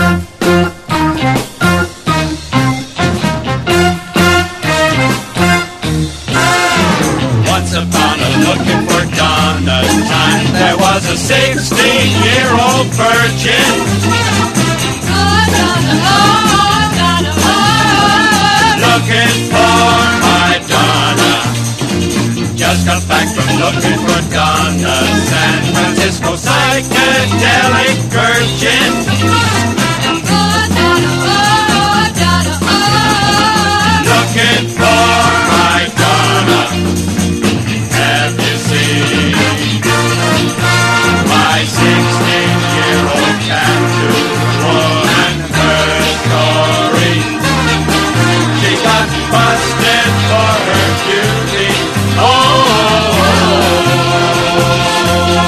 EASY LISTENING / VOCAL / POPCORN
スウィンギーな英国人気クルーナーのノリノリ/ポップコーンな63年作！